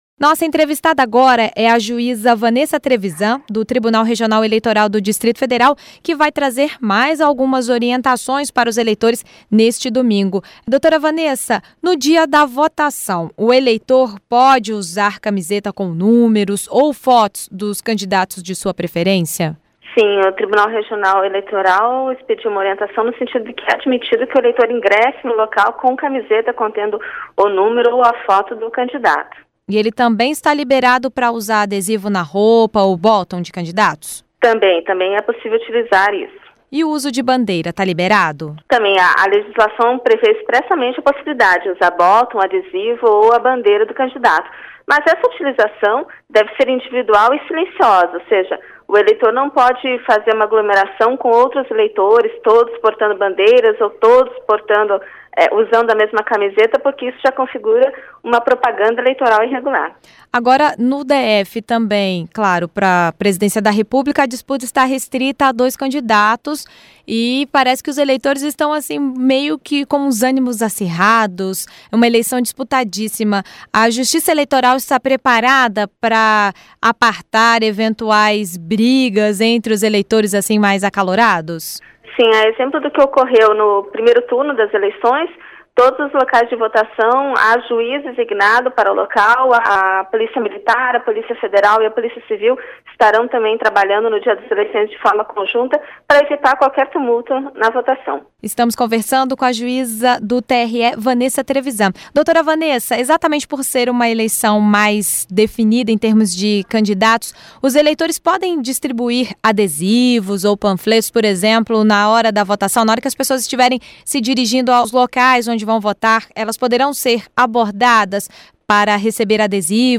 Entrevista com a juíza do Tribunal Regional Eleitoral do DF, Vanessa Trevisan